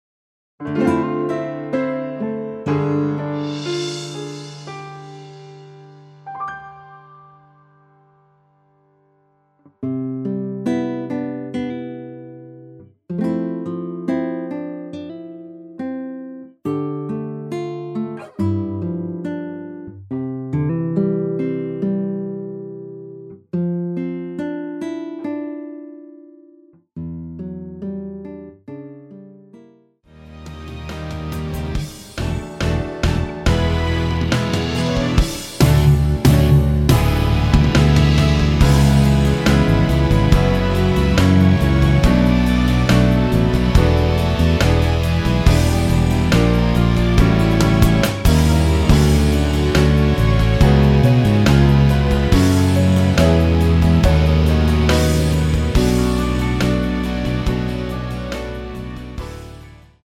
앨범 | O.S.T
앞부분30초, 뒷부분30초씩 편집해서 올려 드리고 있습니다.